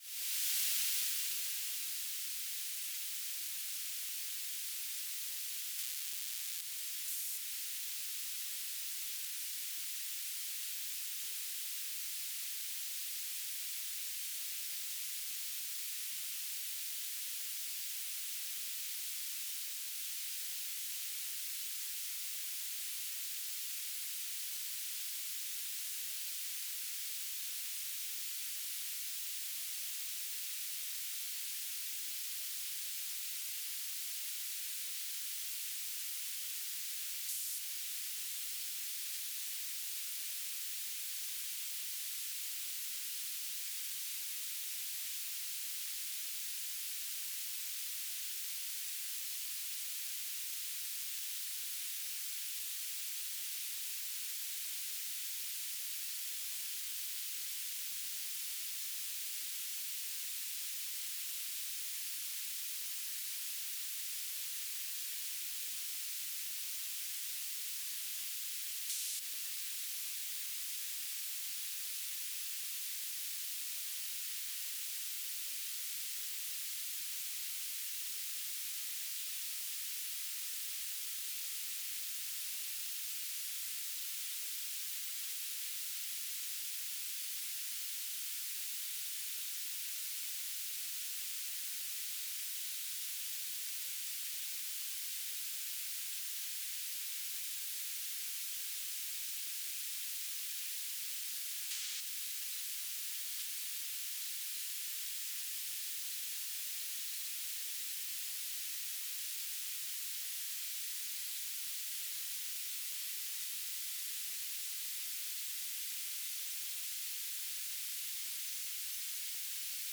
"transmitter_description": "Mode U - BPSK9k6 G3RUH - Beacon",